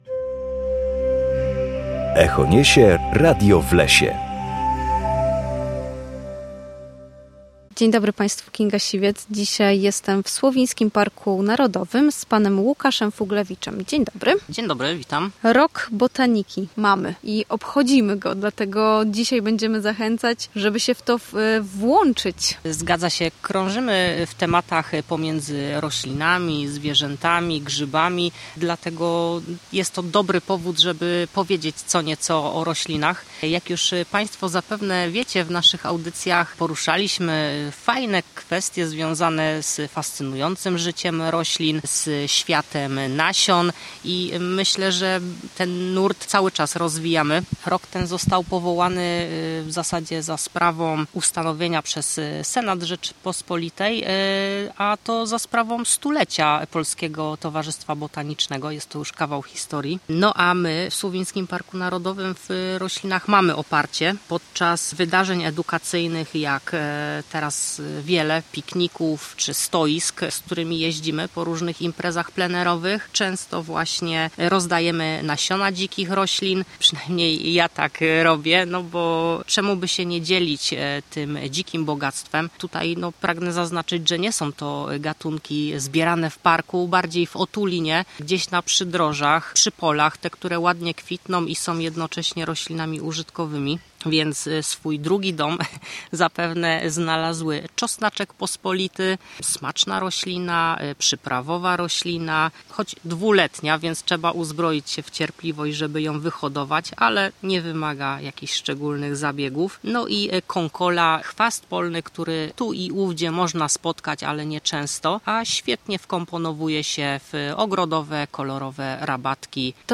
W każdą środę o godzinie 7:20 oraz o 14:10 na antenie Studia Słupsk rozmawiamy o naturze i sprawach z nią